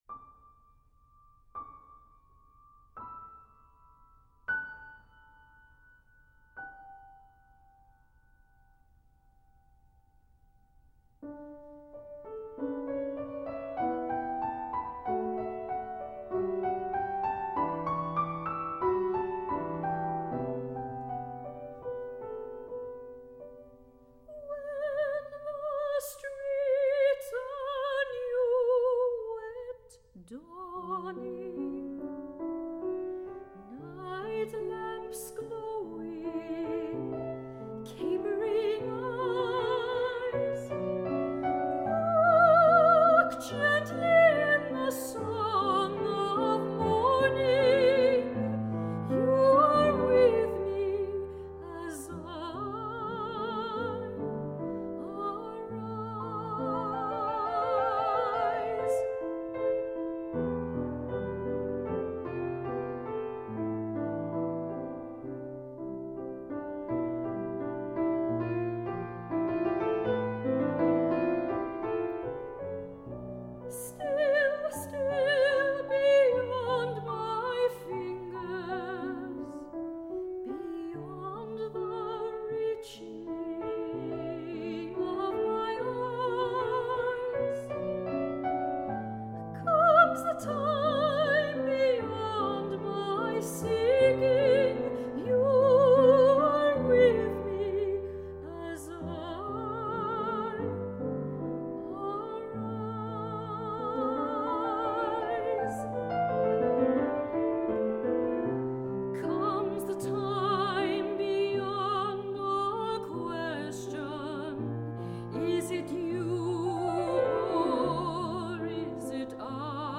for High Voice and Piano (1979)
soprano
piano.